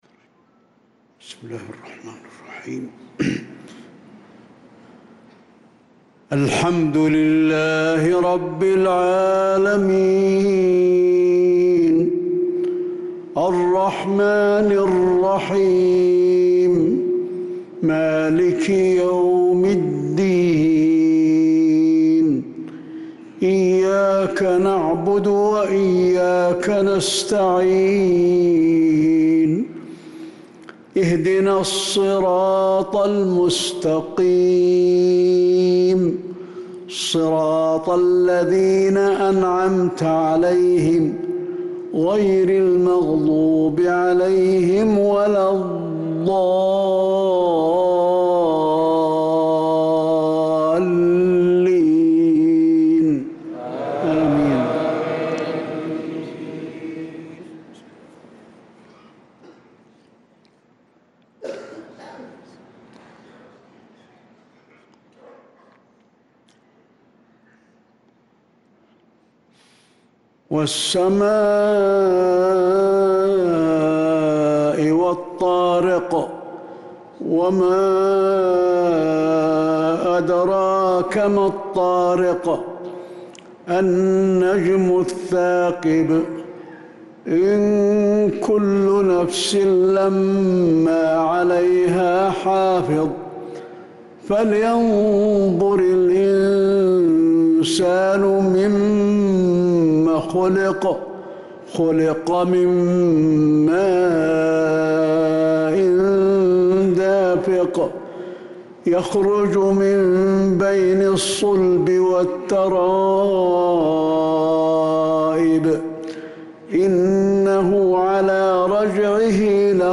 صلاة المغرب للقارئ علي الحذيفي 29 شوال 1445 هـ